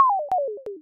computer_a.wav